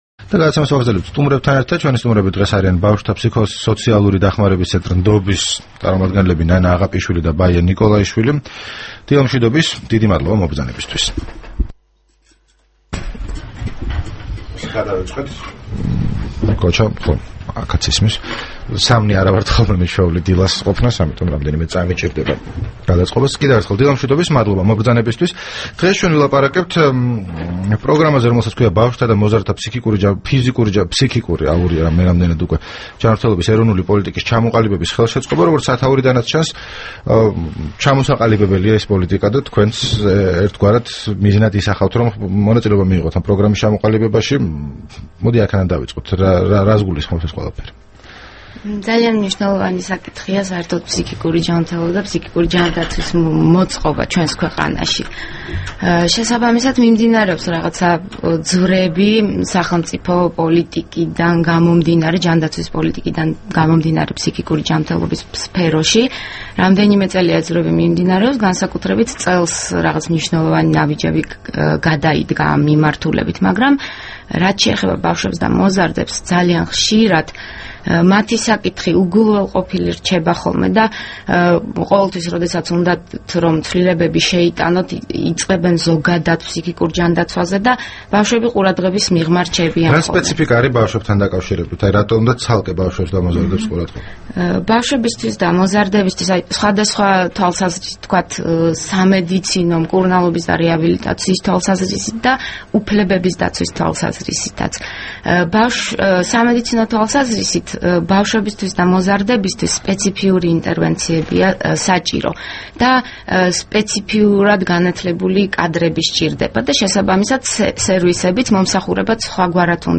რადიო თავისუფლების თბილისის სტუდიაში
საუბარი